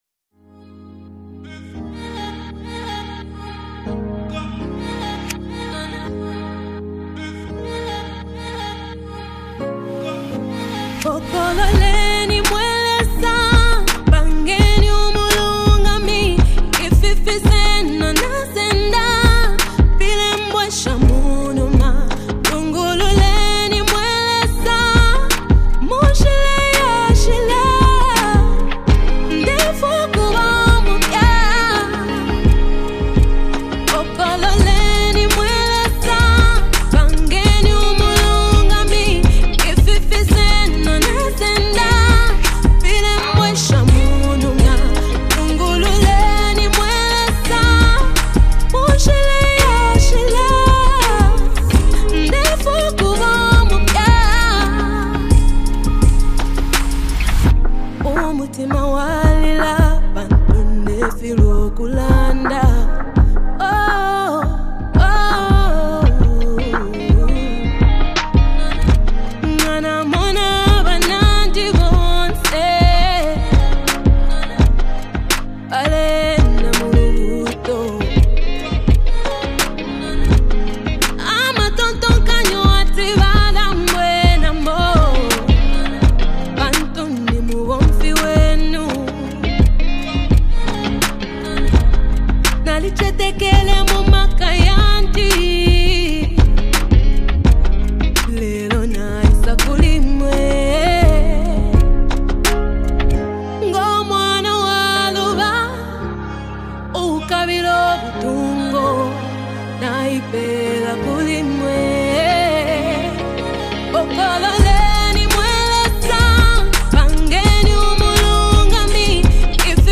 is a powerful anthem of praise and worship
exceptional vocal performance
create an atmosphere of reverence and adoration
gospel